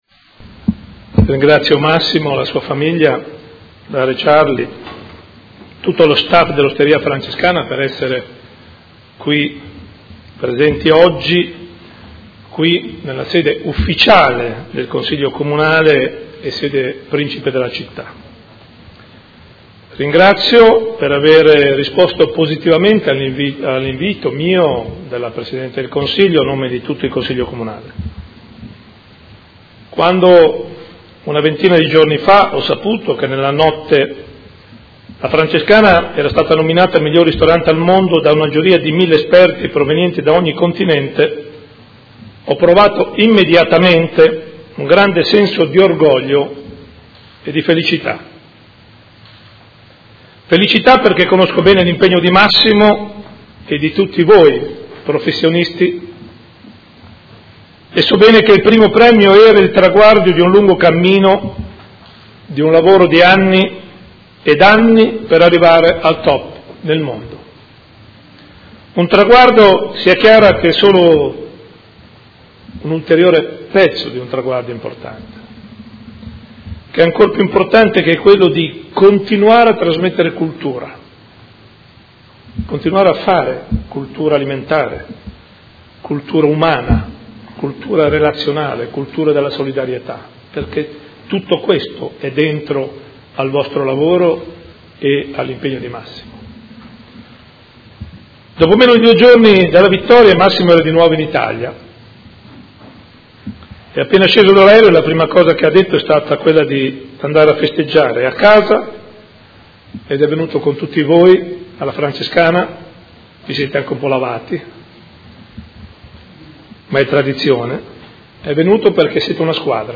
Seduta del 7 luglio. Saluto del Sindaco e consegna di una targa allo chef stellato Massimo Bottura ed al suo staff